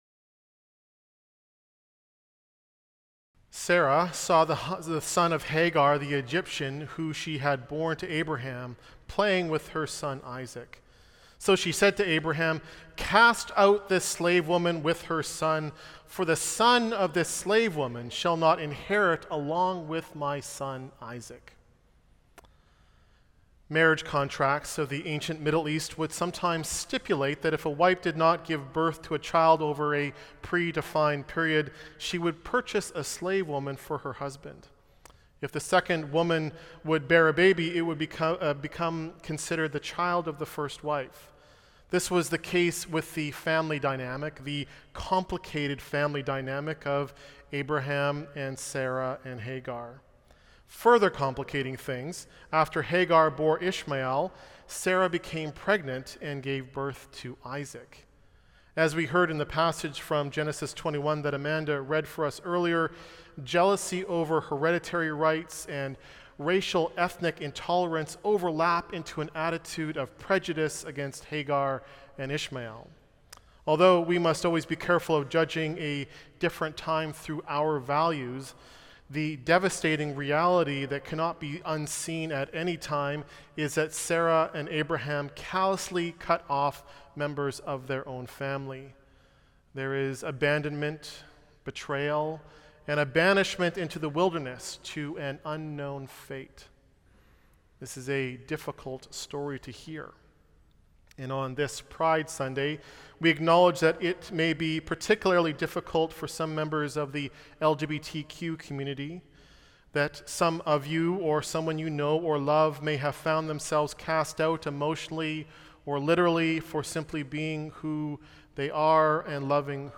Sermon Notes
This week is our Pride service and will feature the leadership of Met’s Affirm Committee, the sacrament of baptism, and some fabulous musical selections from our youth band, Great Heart.